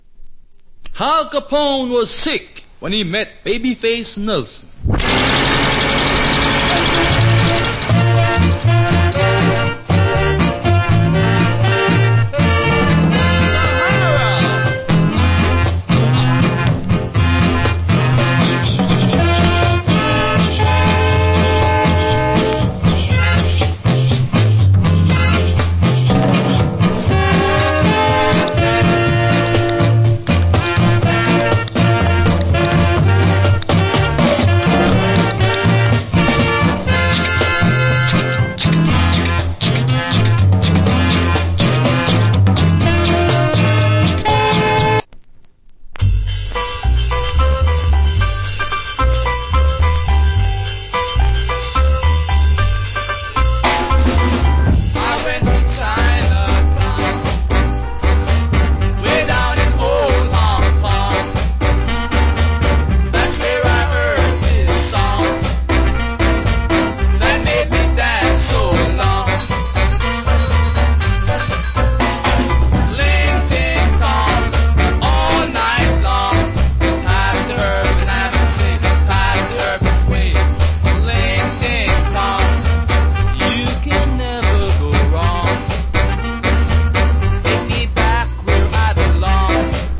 Wicked Ska Inst.